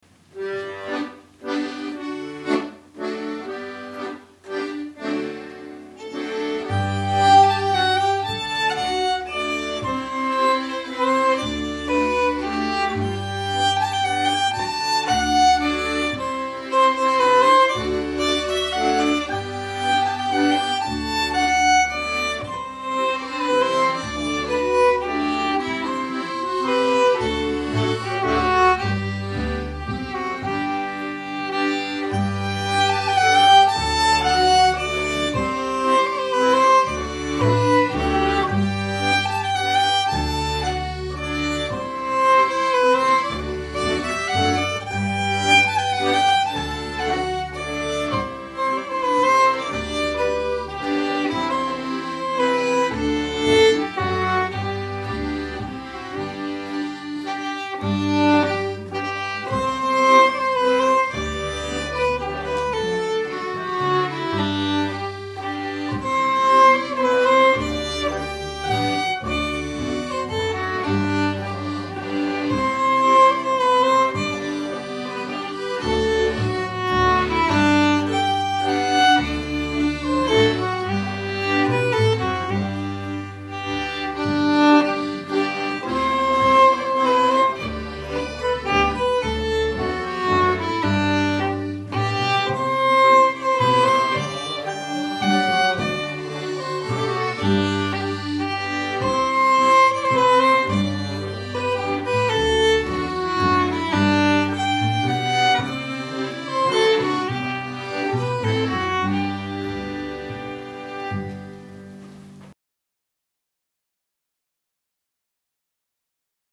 Waltz - G Major